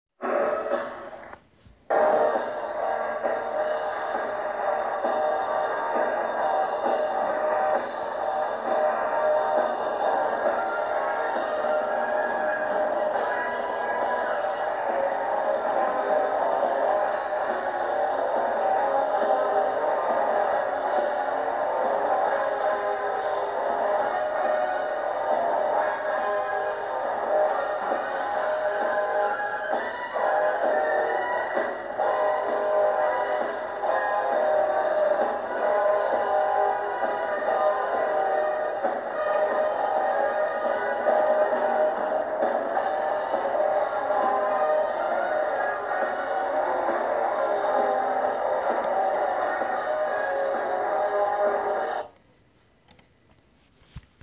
* (Before the age of cell phones I brought my little micro-cassette recorder and made a crude but cool recording of the circus from inside the band I played with during the next to last show on Saturday.